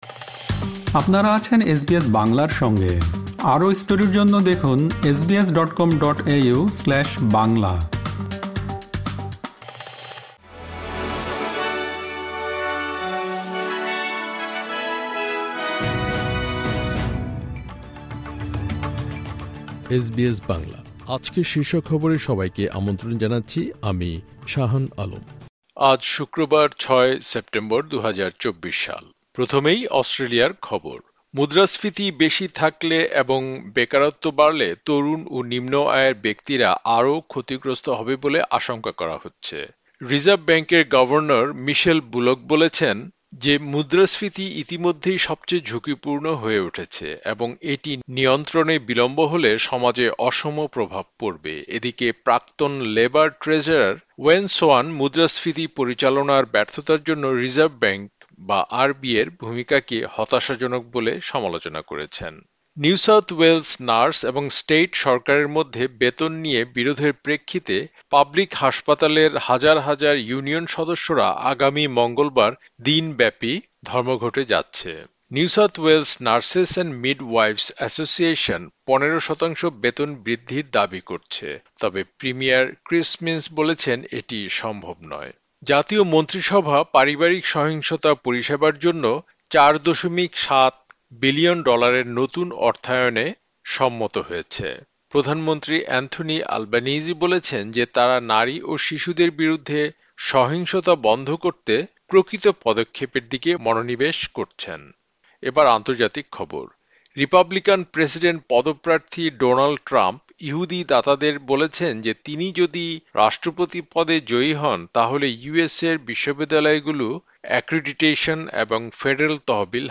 এসবিএস বাংলা শীর্ষ খবর: ৬ সেপ্টেম্বর, ২০২৪